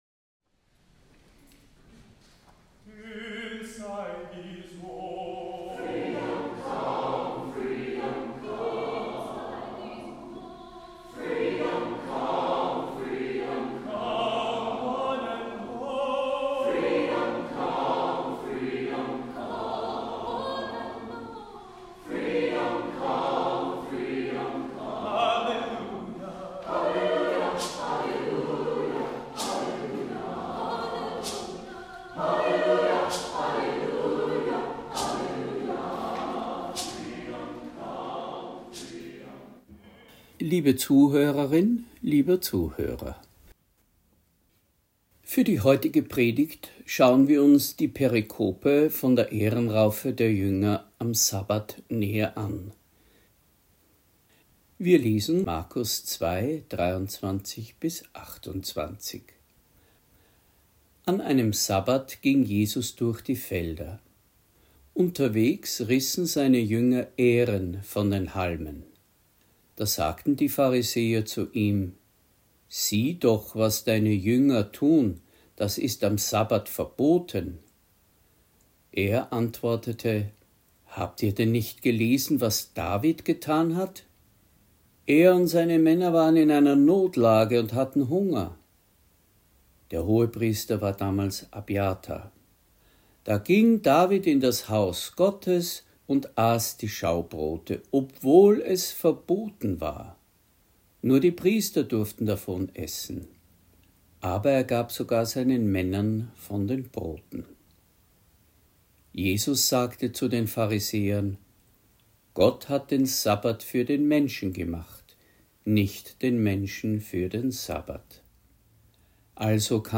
Predigt | NT02 Markus 2,23-28 Die Ährenraufe der Jünger am Sabbat